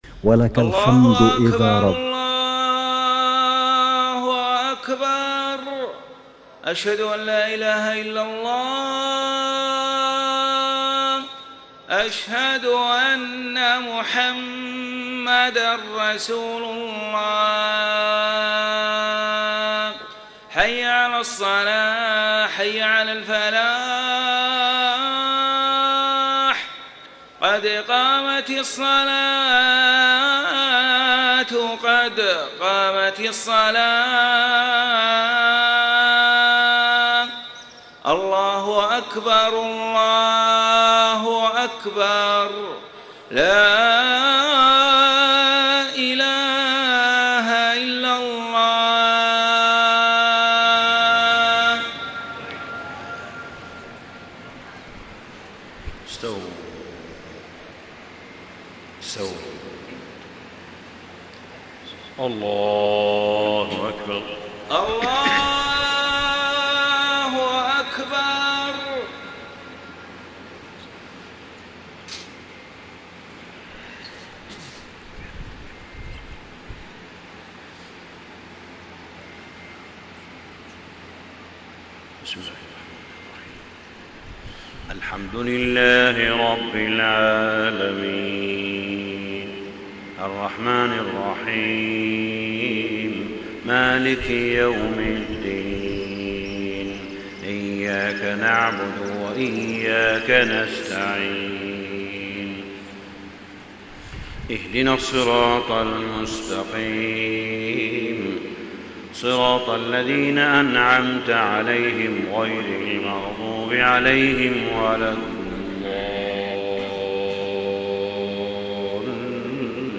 صلاة الفجر 1-9-1434 من سورة البقرة 183-189 > 1434 🕋 > الفروض - تلاوات الحرمين